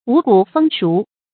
發音讀音
成語簡拼 wgfs 成語注音 ㄨˇ ㄍㄨˇ ㄈㄥ ㄕㄨˊ 成語拼音 wǔ gǔ fēng shú 發音讀音 常用程度 常用成語 感情色彩 中性成語 成語用法 作定語；指豐收在望 成語結構 主謂式成語 產生年代 古代成語 近義詞 五谷豐登 成語例子 清·陳忱《水滸后傳》第30回：“石城堅固， 五谷豐熟 ，人民富庶。”